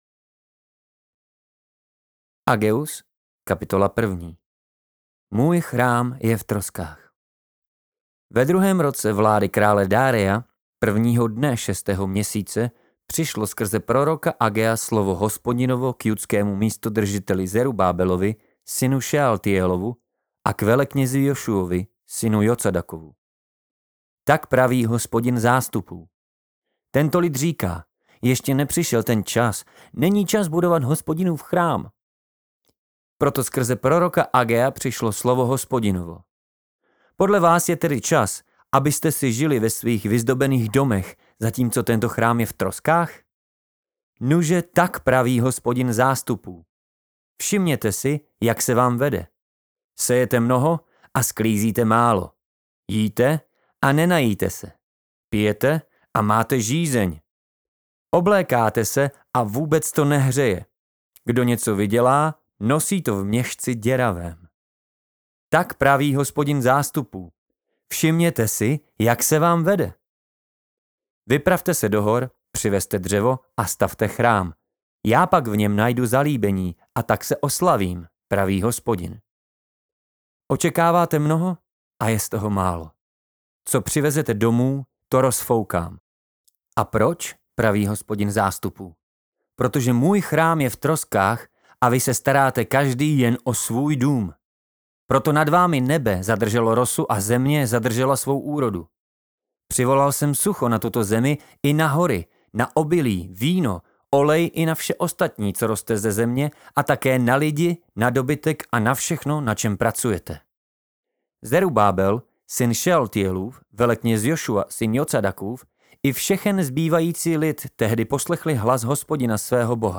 Zajistím potlačení šumů a hluků, výsledný produkt bude dostatečně HLASITÝ, a celkový zážitek z Vašeho pořadu bude velmi příjemný.